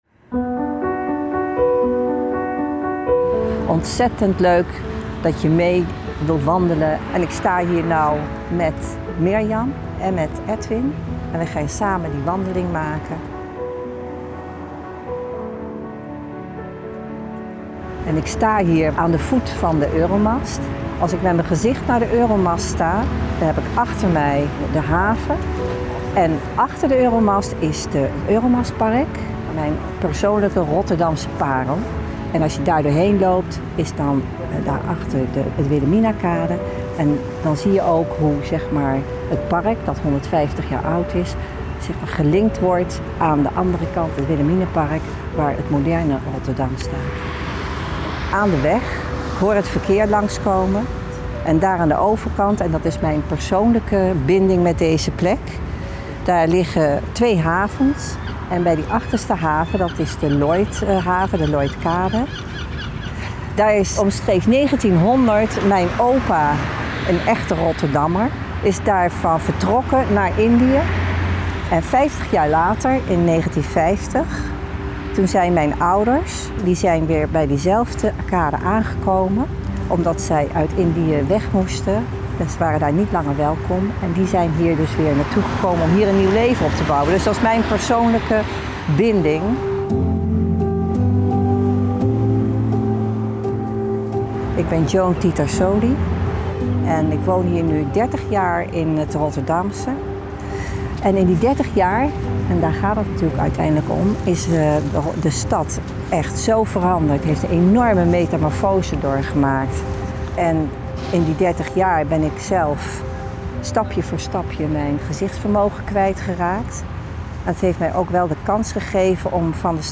Audiotour – Rotterdam met je ogen dicht – Oren en Ogen Tekort
Oren-en-ogen-tekort-Park-Rotterdam-v3.mp3